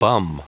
Prononciation du mot bum en anglais (fichier audio)
Prononciation du mot : bum